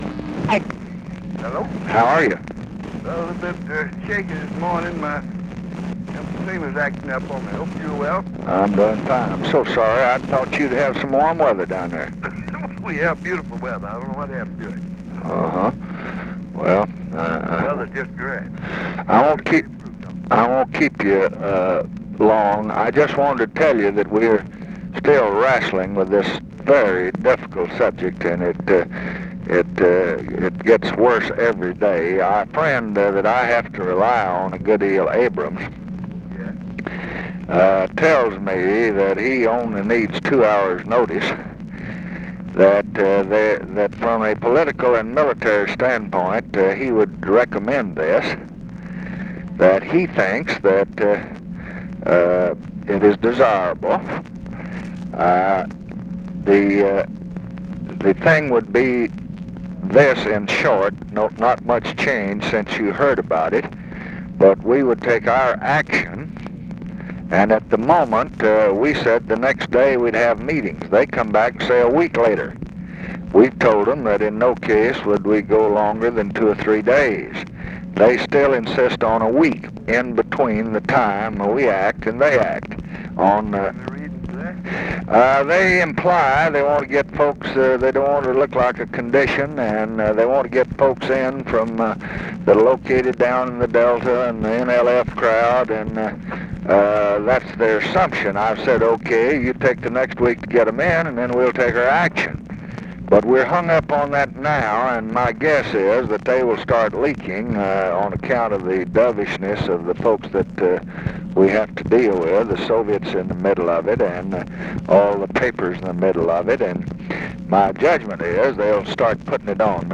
Conversation with RICHARD RUSSELL, October 23, 1968
Secret White House Tapes